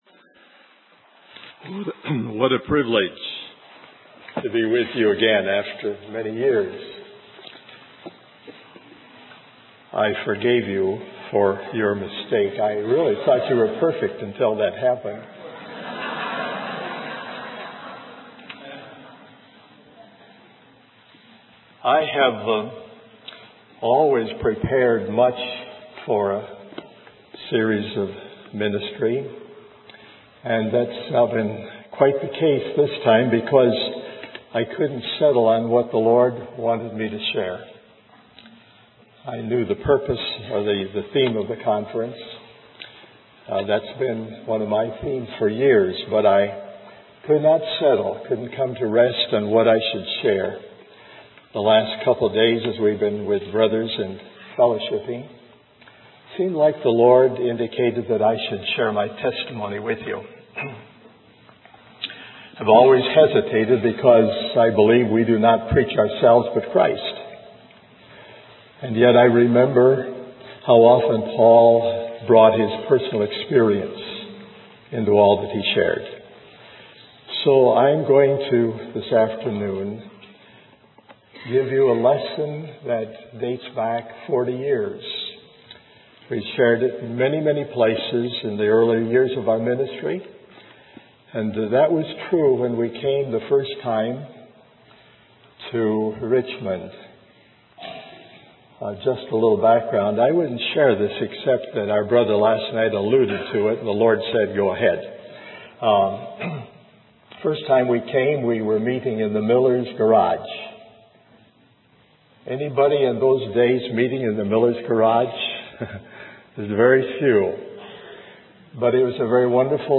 In this sermon, the speaker reflects on his 60 years of ministry and shares a basic lesson on God's purpose as depicted in the book of Genesis. He emphasizes that God not only has a purpose in everything He does, but there are also principles that govern and bring that purpose into being.